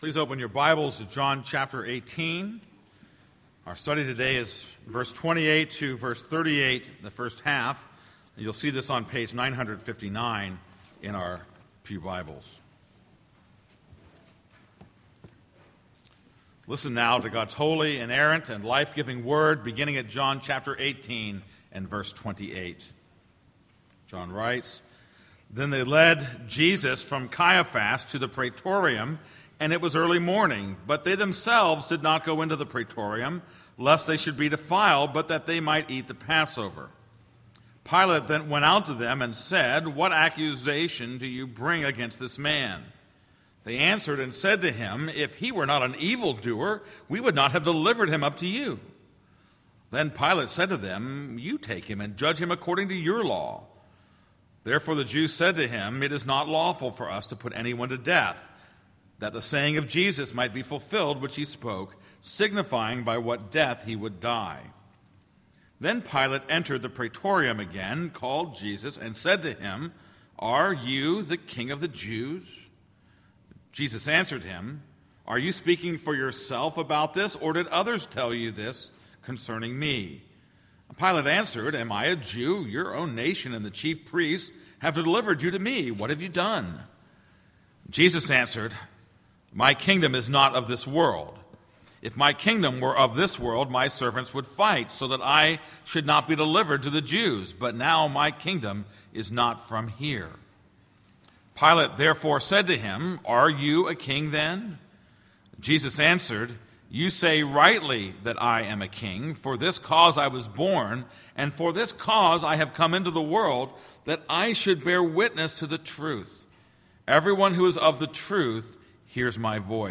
This is a sermon on John 18:28-38a.